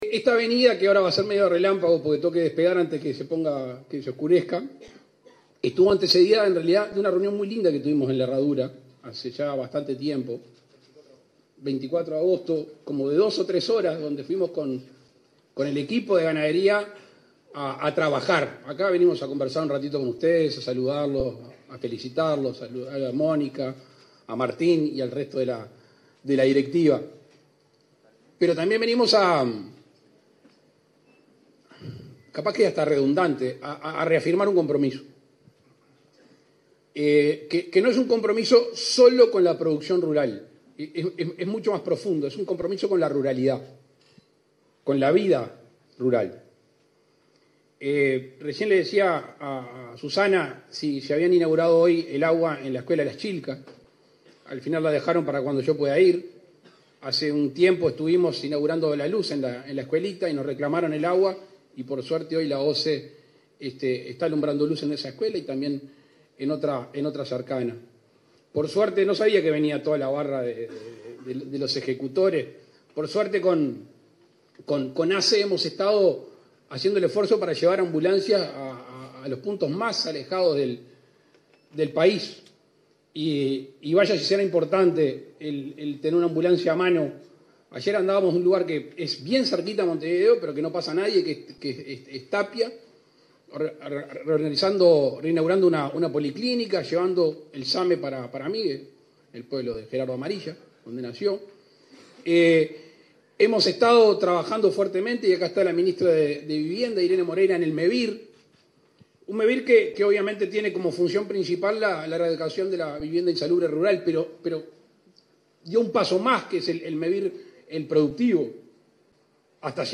Palabras del presidente de la República, Luis Lacalle Pou, en Tacuarembó
El presidente de la República, Luis Lacalle Pou, participó, este 28 de mayo, en la clausura del 105.º Congreso de la Federación Rural, en Tacuarembó.
Discurso .mp3